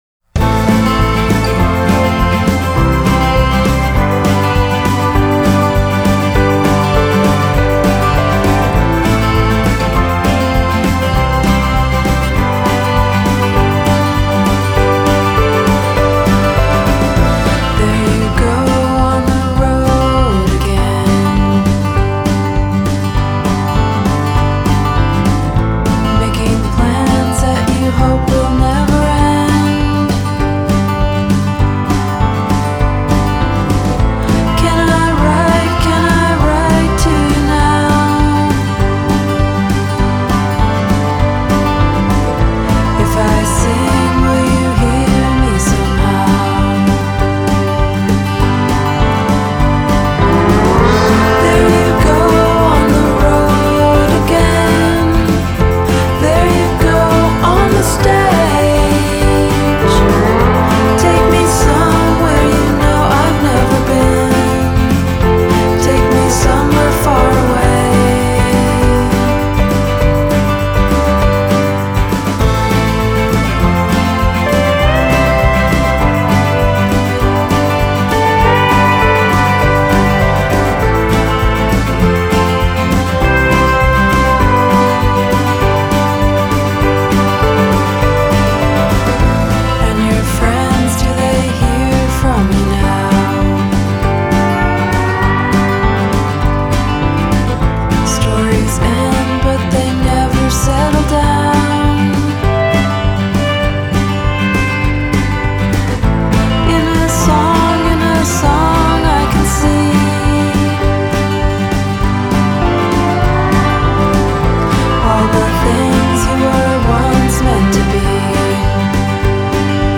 Genre: Indie Pop-Rock / Indie-Folk /